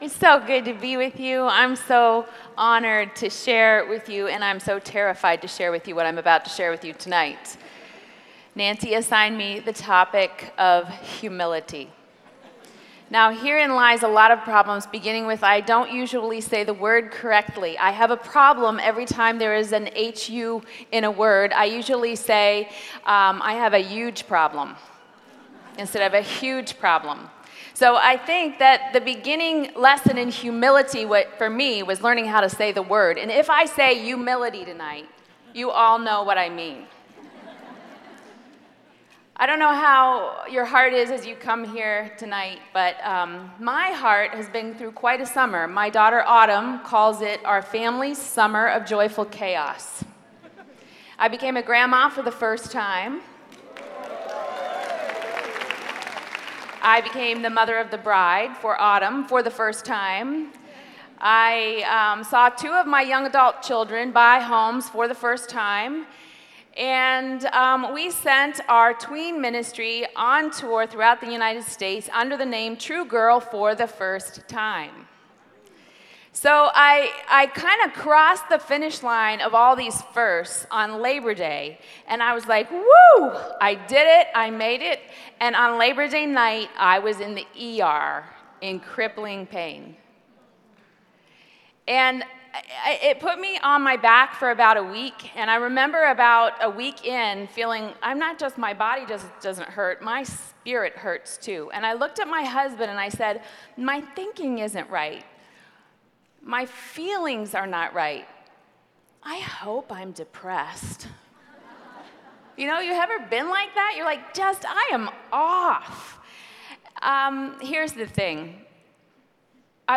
Becoming God’s Dwelling Place | Revive '19 | Events | Revive Our Hearts